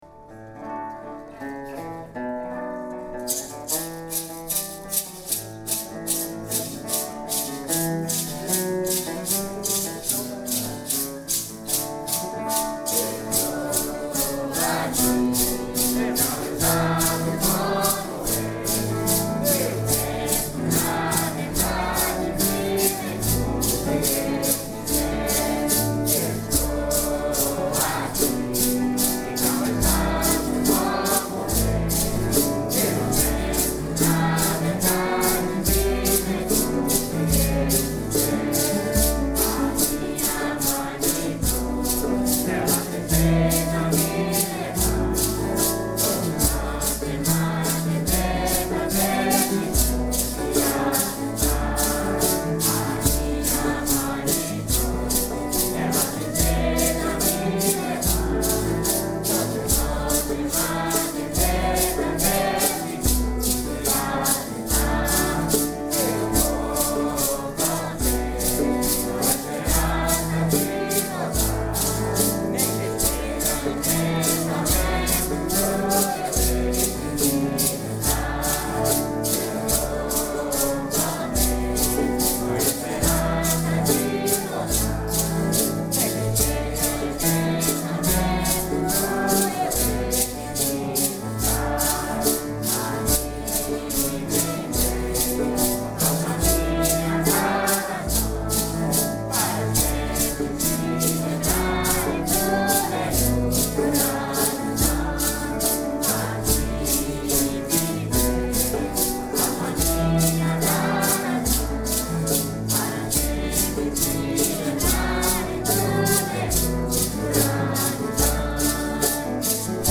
in Portland 2011
marcha